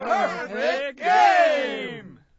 crowd-perfect.wav